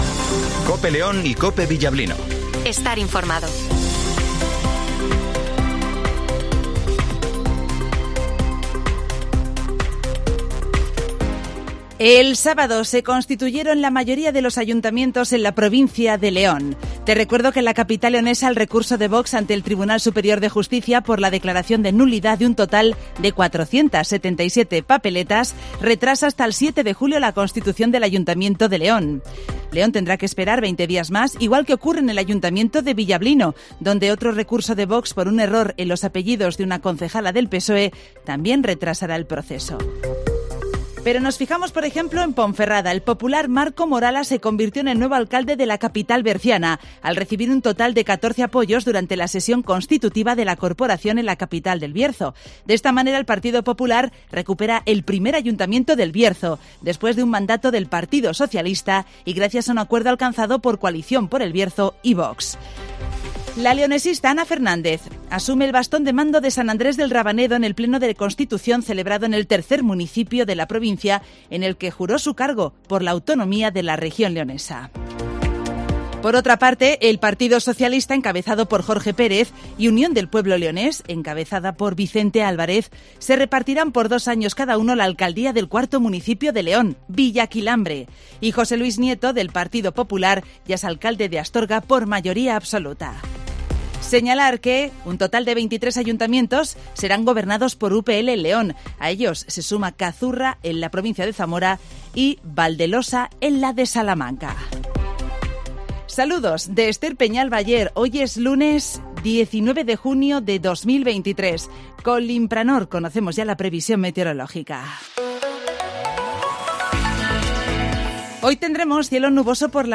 - Informativo Matinal 08:20 h